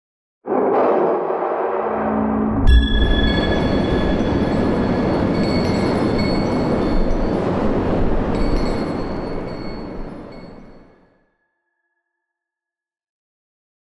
abjuration-magic-sign-circle-intro-fade.ogg